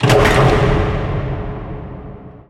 clock_tick.wav